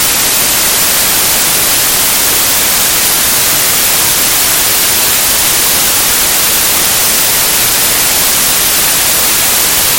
white_noise.mp3